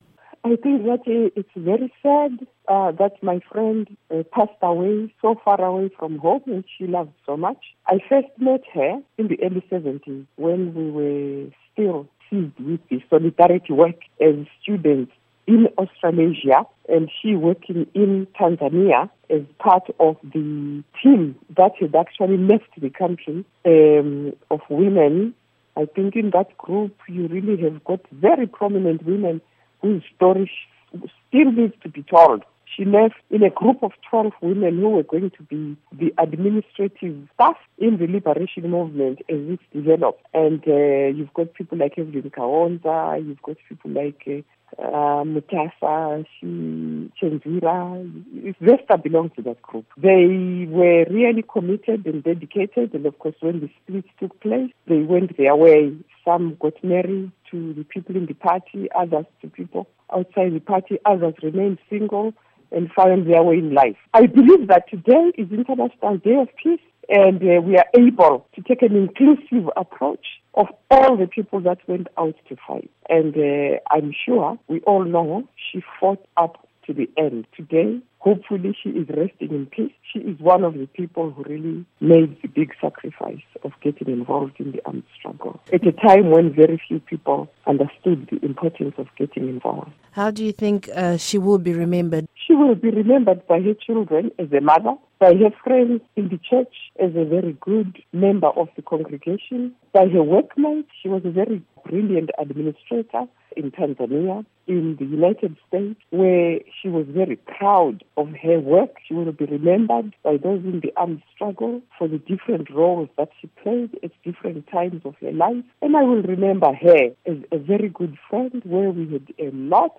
Interview with Sekai Holland